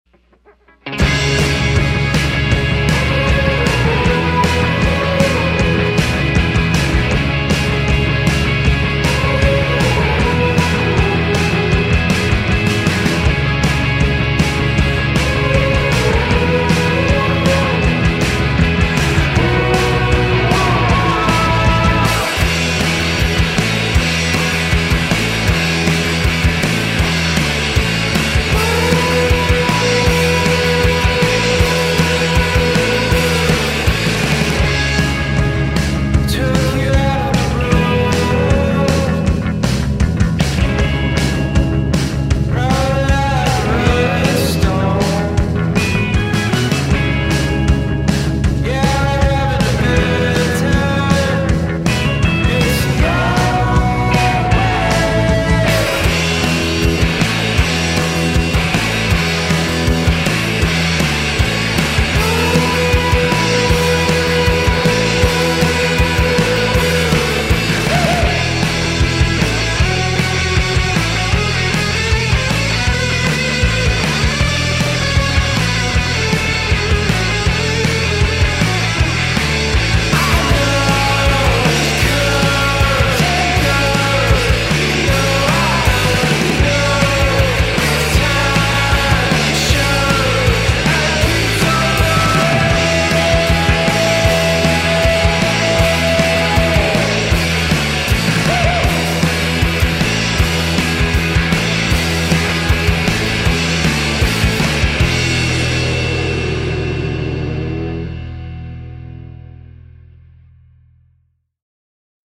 bass
drums. Their music richochets between punk and shoegaze
surf-rock sound